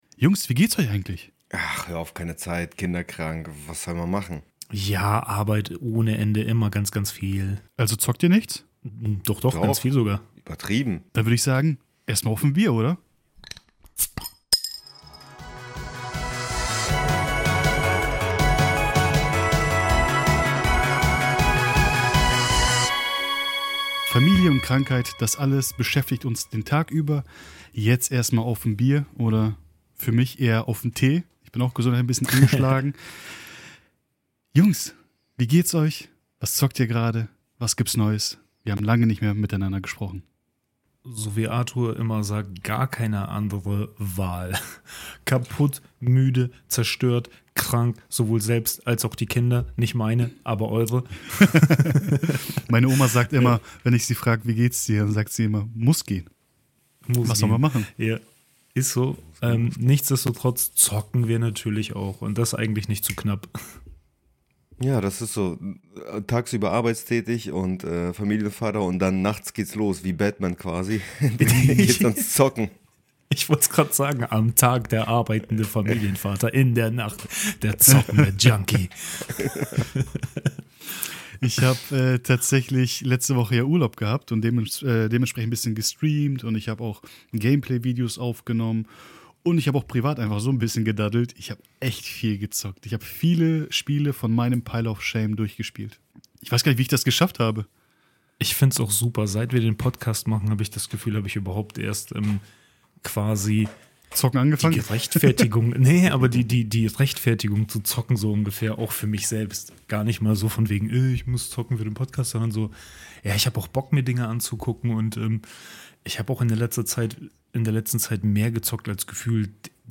Beschreibung vor 5 Monaten Diesmal gibt es einen entspannten Talk zu verschiedensten Themen: Wie gefällt uns Arc Raiders, ist es der Tarkov Killer?